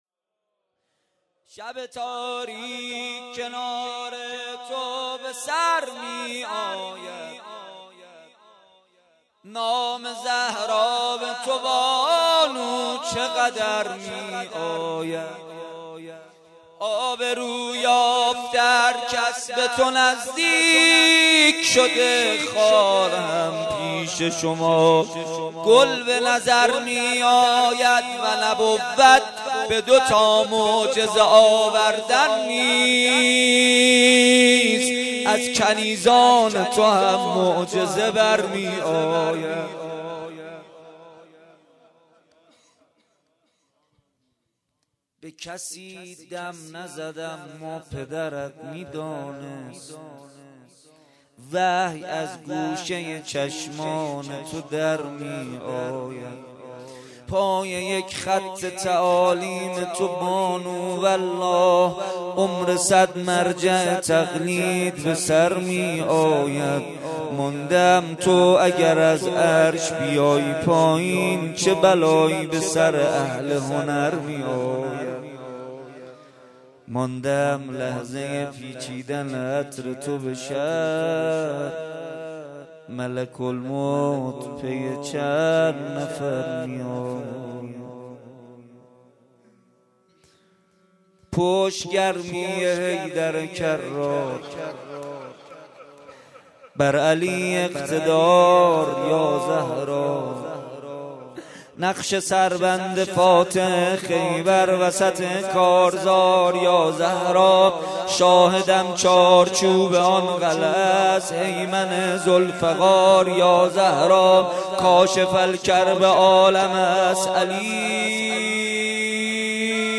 شب هشتم رمضان 96 - ریحانة النبی - روضه حضرت زهرا (س)